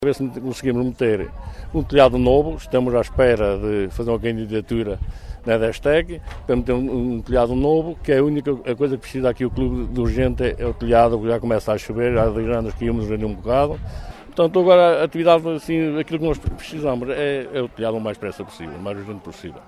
Declarações à margem das comemorações do 37º aniversário do Clube de Caça e Pesca de Macedo de Cavaleiros, onde a data foi marcada com uma missa campal, almoço convívio e ainda uma tarde com atividades desportivas.